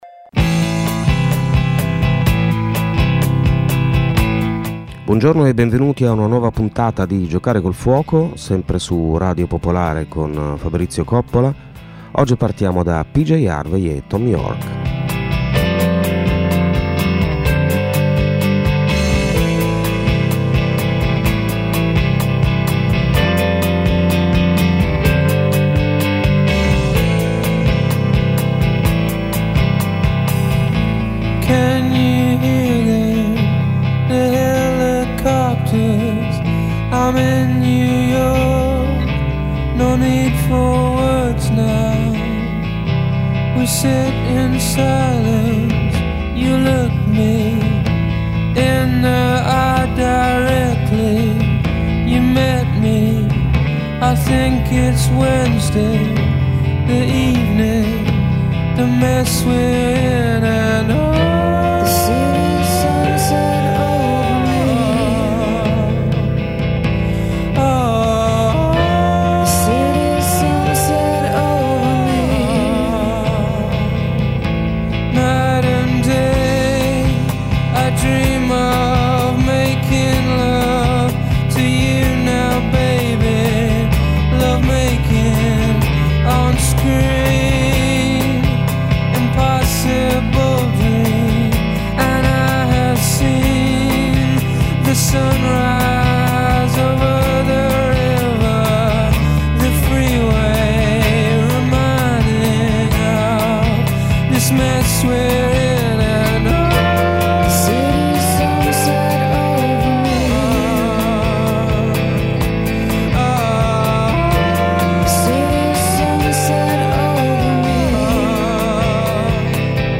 Memoir e saggi, fiction e non fiction, poesia (moltissima poesia), musica classica, folk, pop e r’n’r, mescolati insieme per provare a rimettere a fuoco la centralità dell’esperienza umana e del racconto che siamo in grado di farne.